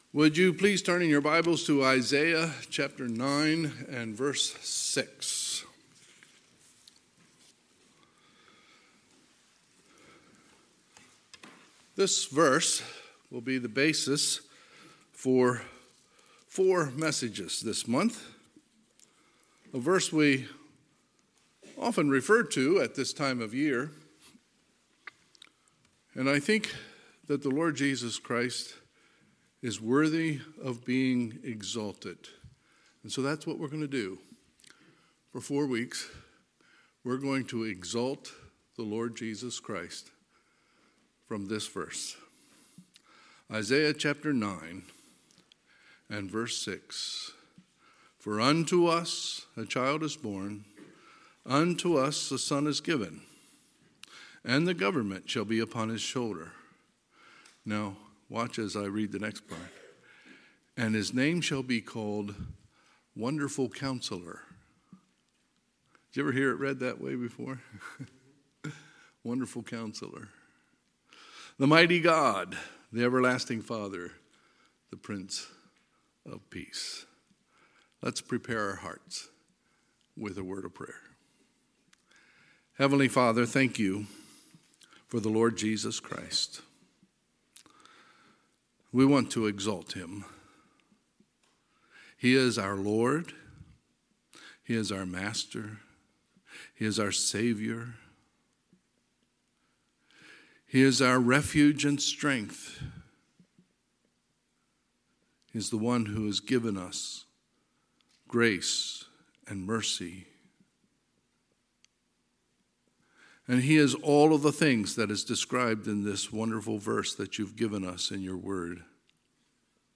Sunday, December 5, 2021 – Sunday AM
Sermons